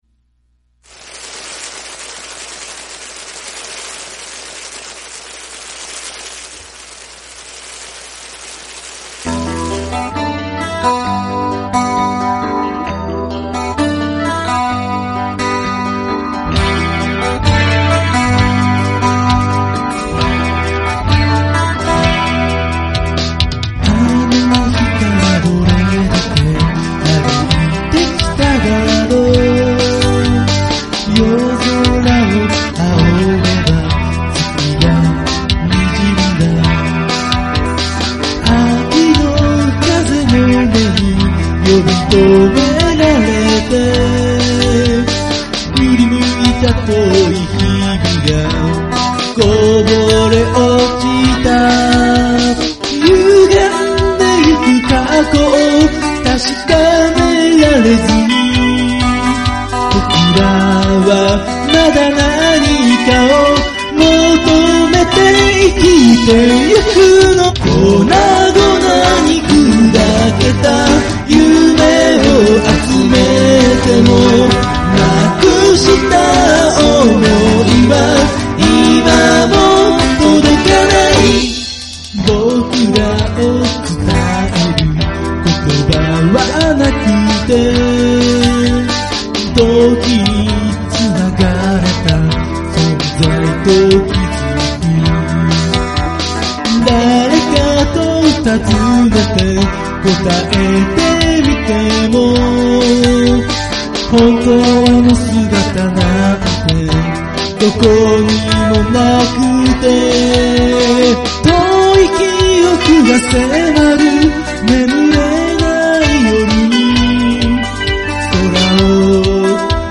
Chorus,Bass,E.guitar,A.guitar,Keyboard,Drums